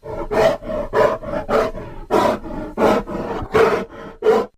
Звуки гризли
Активно дышит